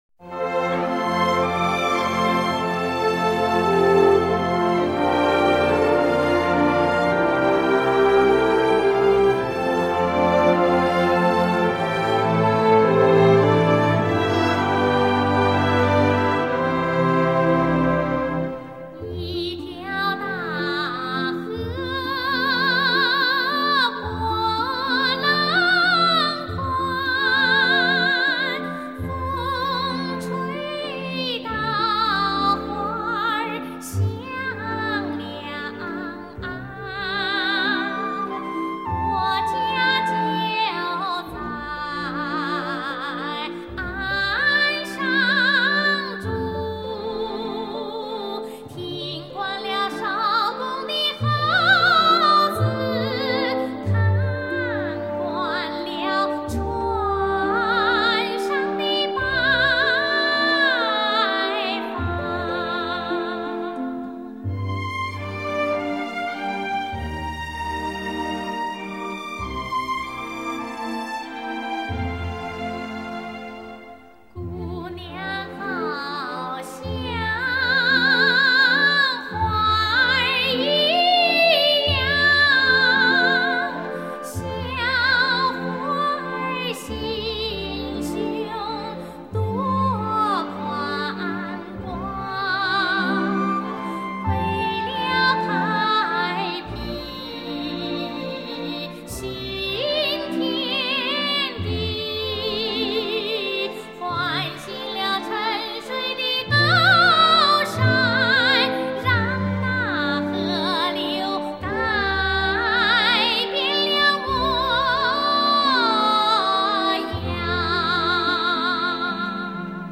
八十年代初模拟录音的最珍贵版本